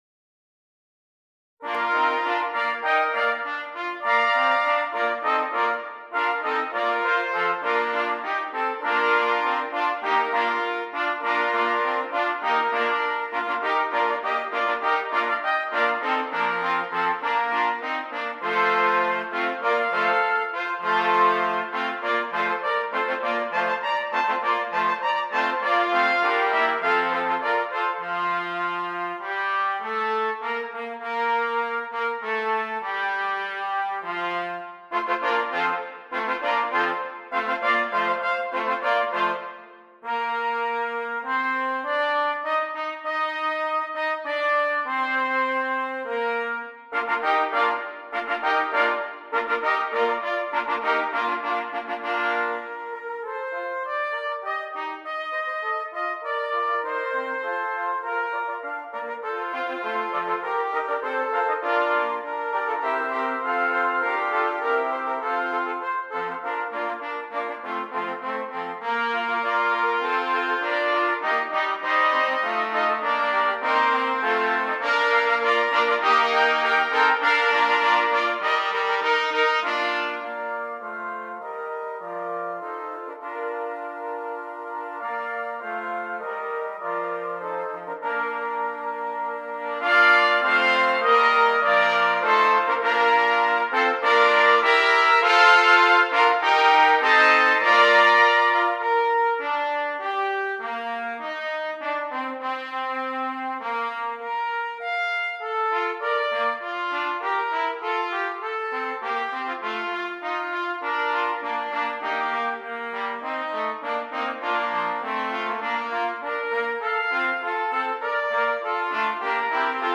Brass Band
6 Trumpets